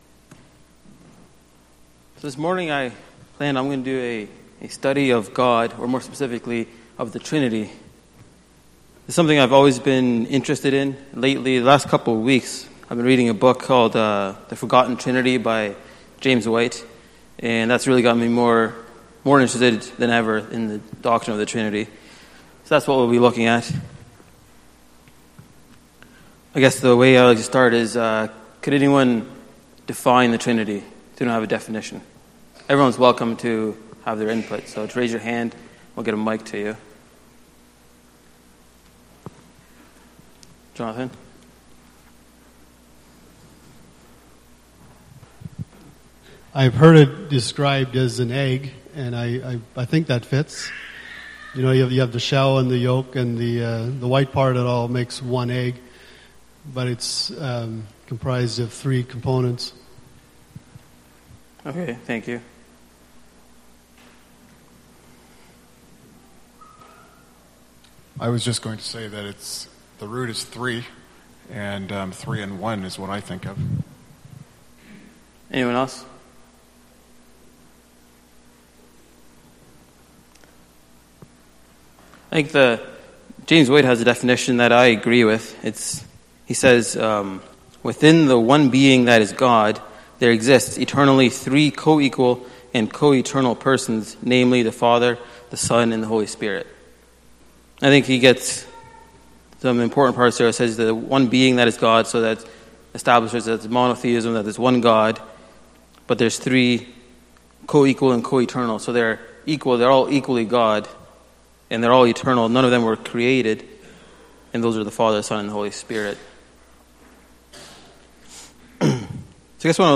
Sunday Morning Bible Study Service Type: Sunday Morning %todo_render% « Where Do You Go?